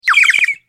دانلود صدای پرنده 2 از ساعد نیوز با لینک مستقیم و کیفیت بالا
جلوه های صوتی